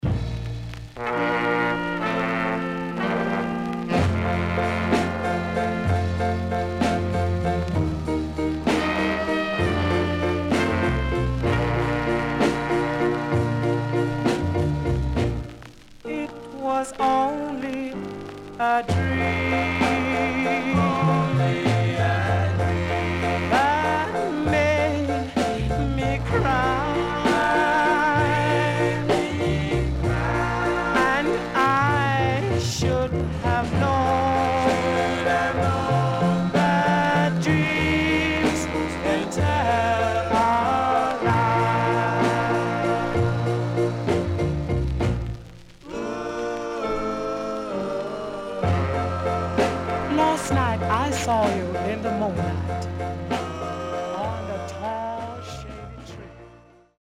HOME > SKA / ROCKSTEADY  >  SKA  >  BALLAD  >  INST 60's
SIDE A:全体的にチリノイズがあり、少しプチノイズ入ります。